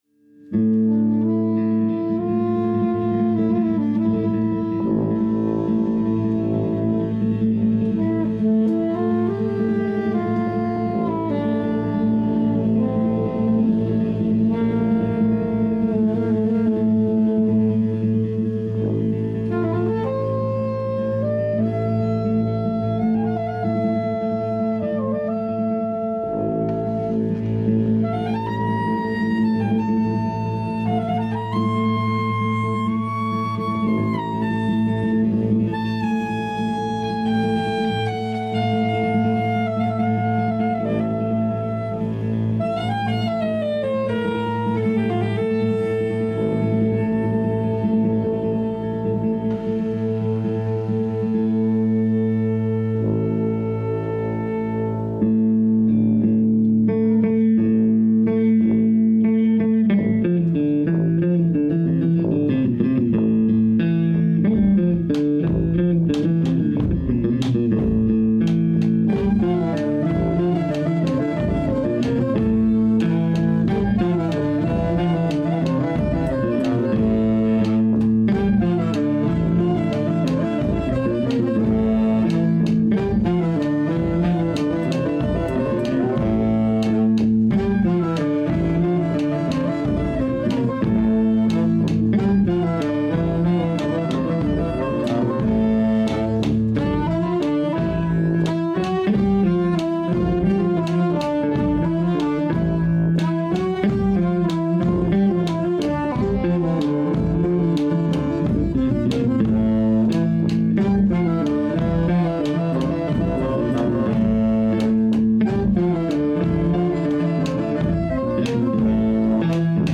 Morceaux choisis — Fanfare du Château Genève
Eptasimos Xoros Traditionnel Grèce